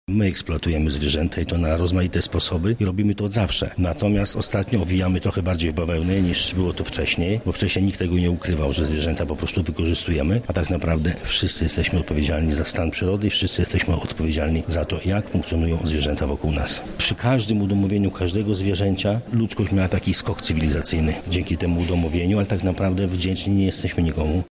Nad wspólną egzystencją ludzi i zwierząt zastanawiali się dzisiaj słuchacze wykładu doktora Andrzeja Kruszewicza, autora wielu publikacji o przyrodzie, a także dyrektora warszawskiego zoo. On sam twierdzi, że jesteśmy okrutni wobec naszych braci mniejszych: